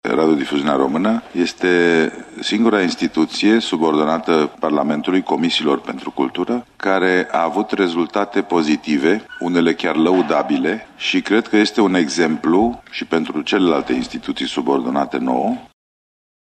Deputatul PSD, Mădălin Voicu: